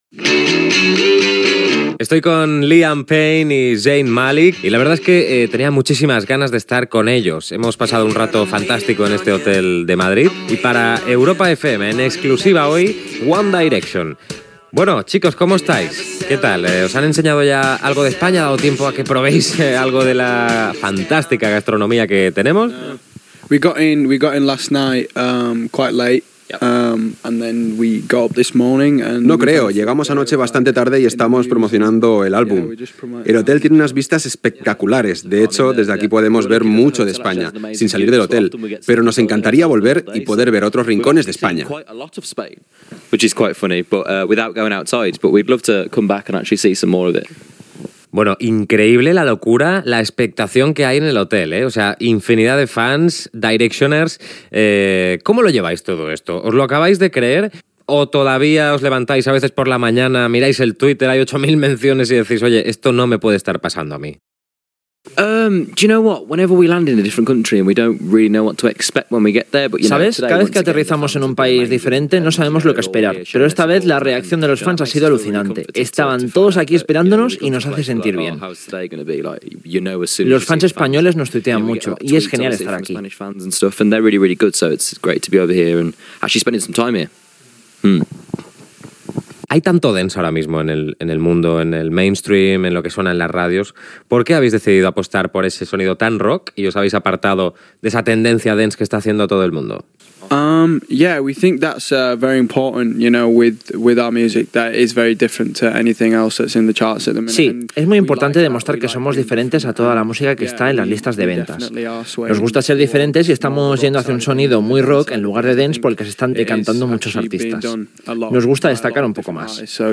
Entrevista als integrants del grup One Direction: Liam Payne i Zayn Malik